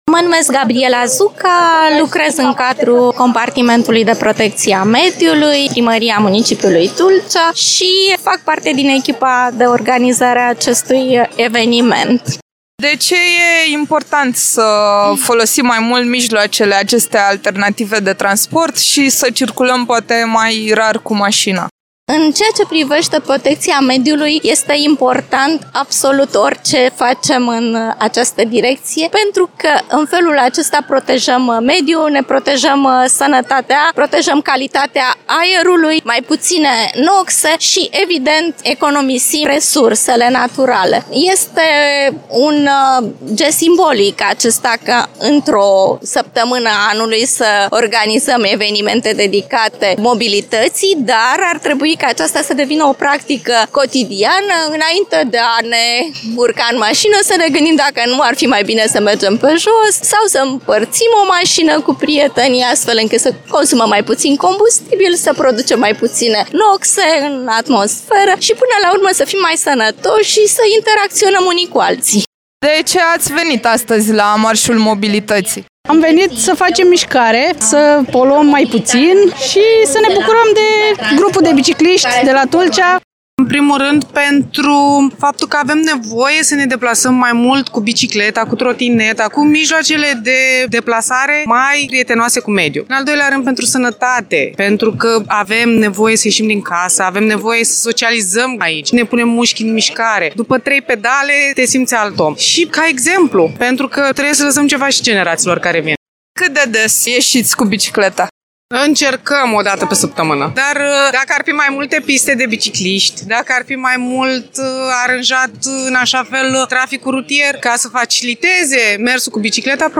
Un reportaj